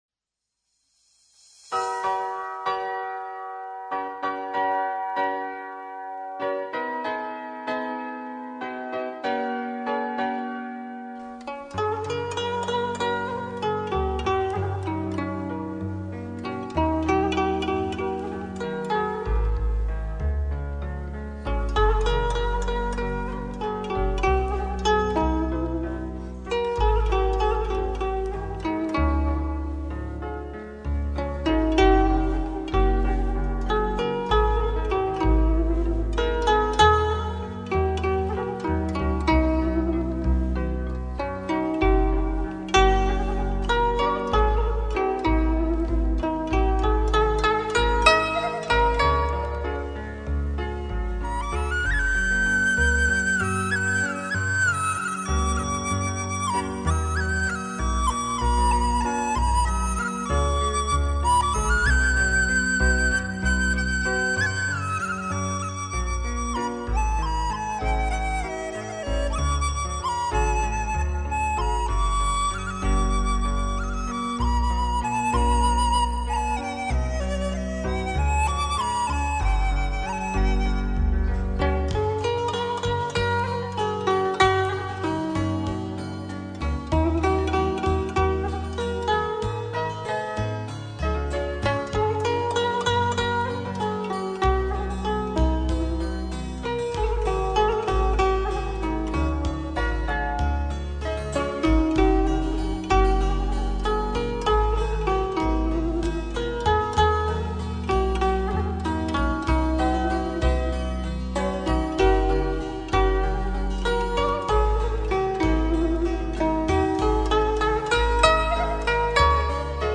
依據中醫原理，為現代人精心調配的養生樂方，曲風柔美
自然舒放，是您最佳的心靈排毒妙方，天天聽，讓您健康又愉快
最優質的東方健康音樂